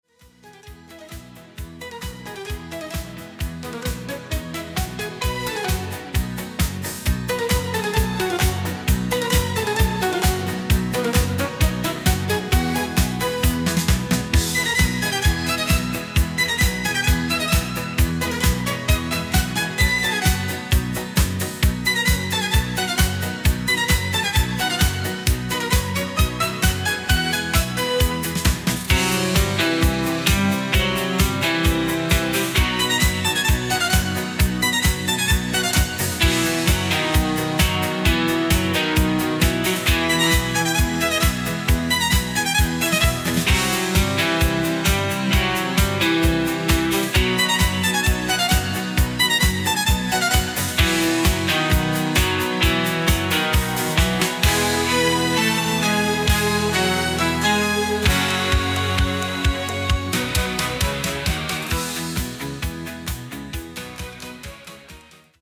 Nicht ganz modern, aber schwungvoll
Musik